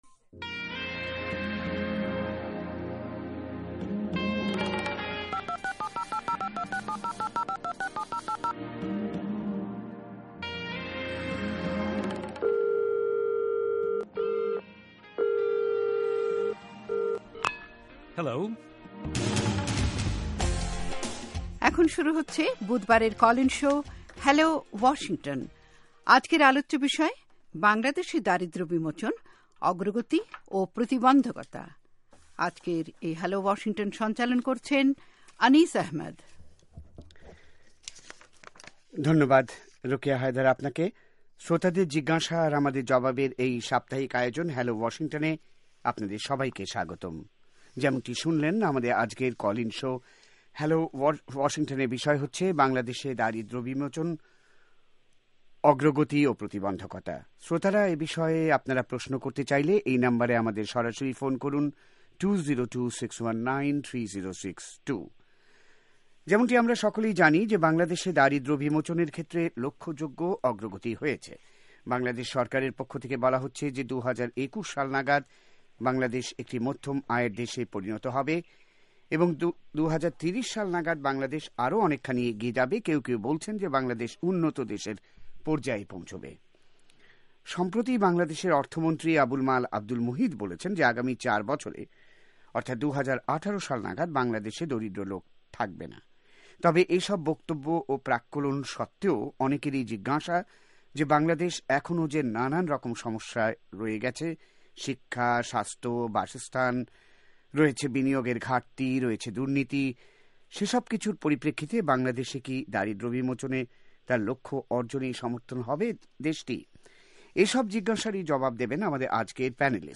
শুনুন কল ইন শো : হ্যালো ওয়াশিংটন